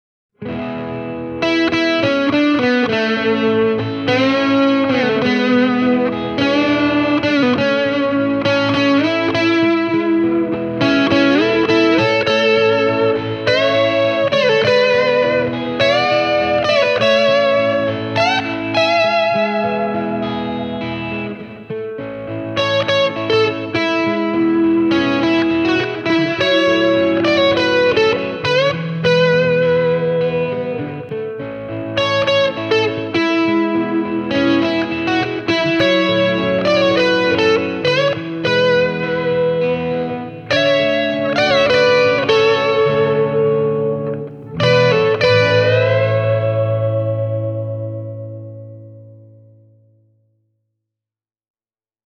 Vahvistimen läpi tämä pieni ero kuitenkin häviää, ja Edwardsin ja referessikitaran väliset pienet soundilliset erot johtuvat varmaan enemmän soittimien eri mikrofoneista kuin niiden perusäänistä.